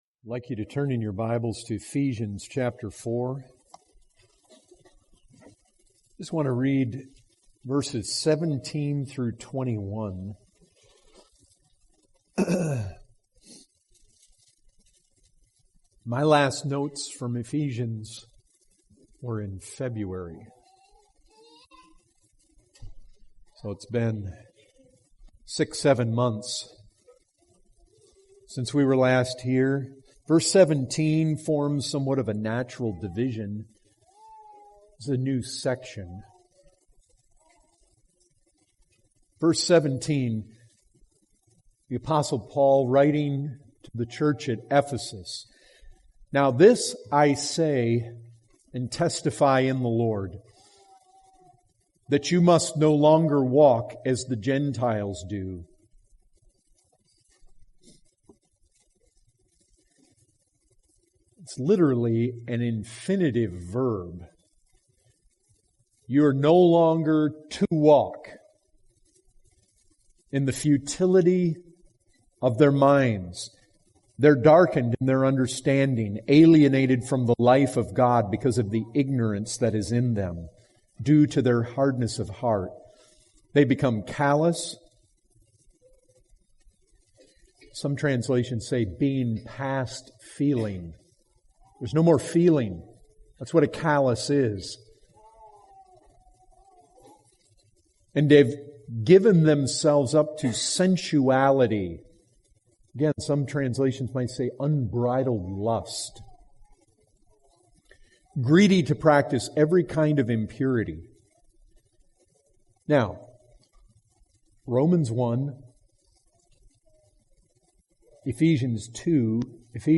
2019 Category: Full Sermons Being a Christian is not just learning facts about Christ